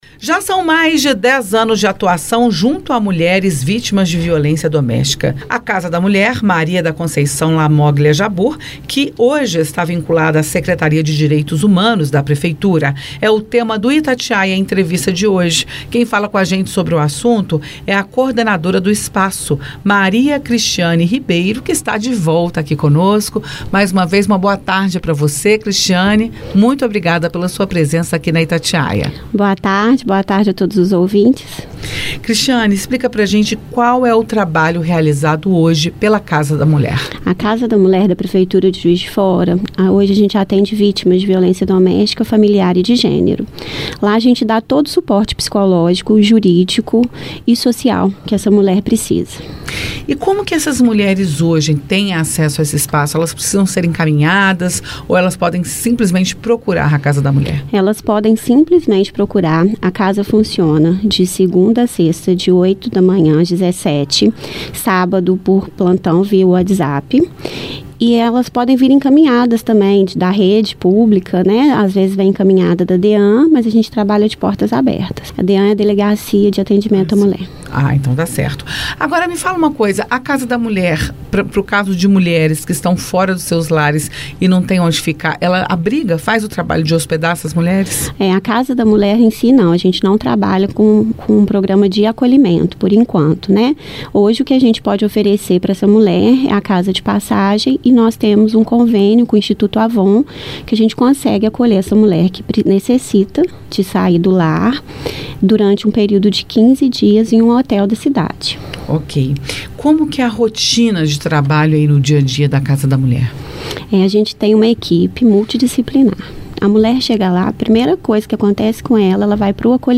Itatiaia-Entrevista-Casa-da-Mulher.mp3